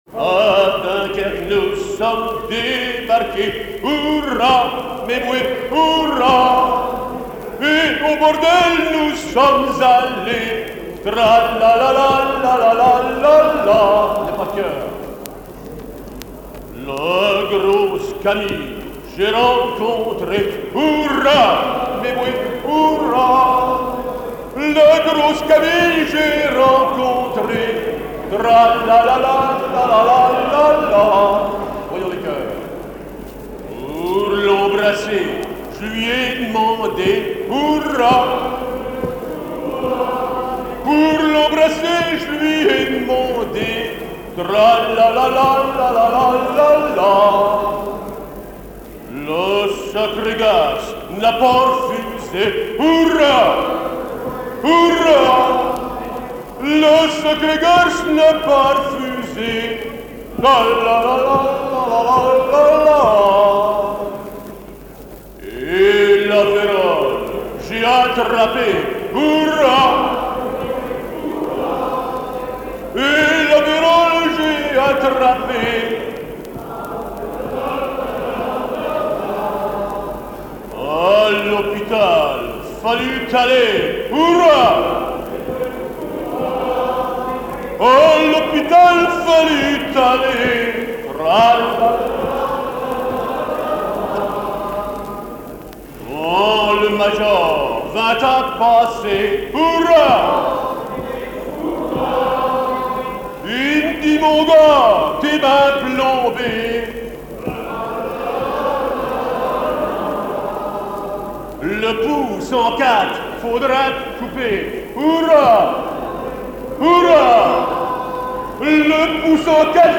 gestuel : à hisser à grands coups
circonstance : maritimes
Genre laisse
Pièce musicale éditée